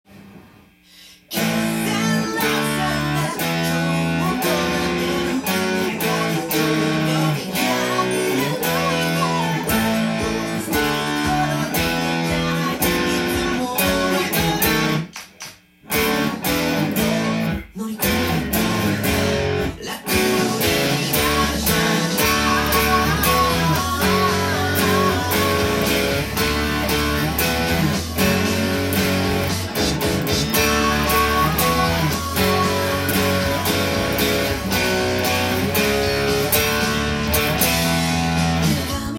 音源にあわせて譜面通り弾いてみました
パワーコードです。